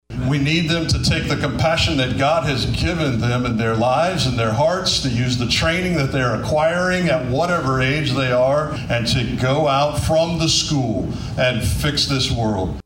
The local event for NDP was held at HeartMatters, located at 3401 Price Road in Bartlesville, on Thursday evening.